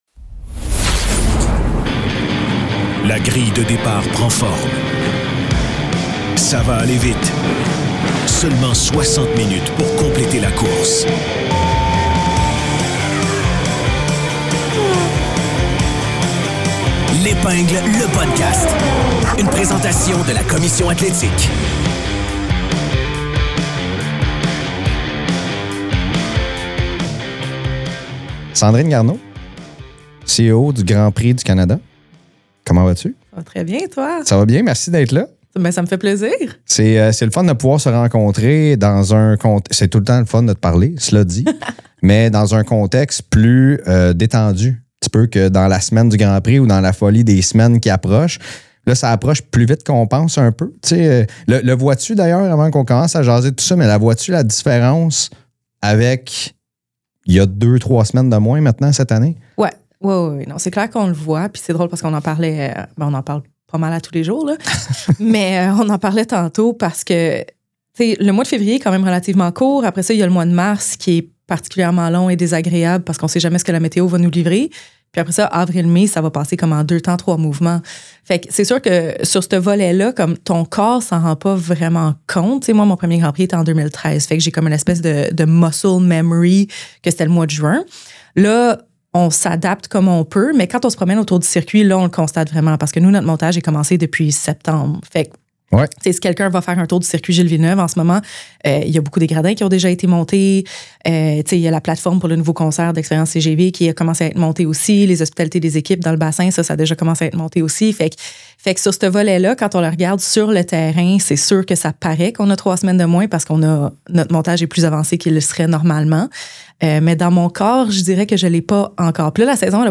Entrevue exclusive